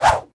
footswing5.ogg